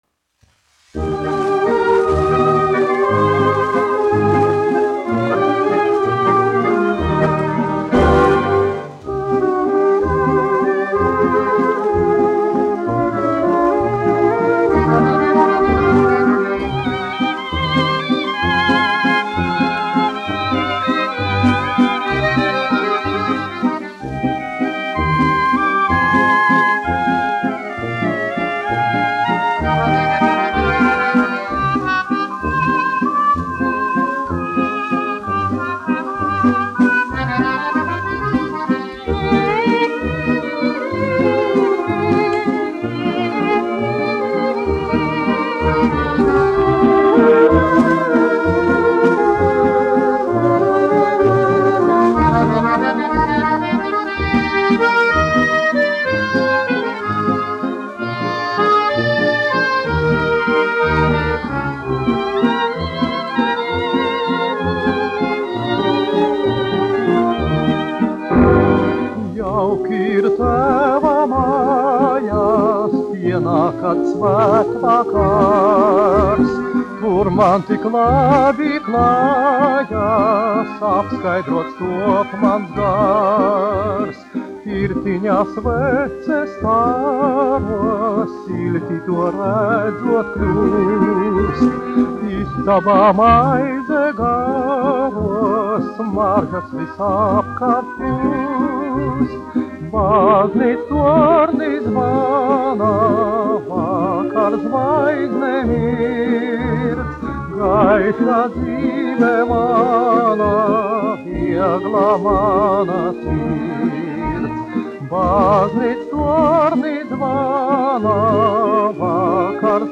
1 skpl. : analogs, 78 apgr/min, mono ; 25 cm
Populārā mūzika
Skaņuplate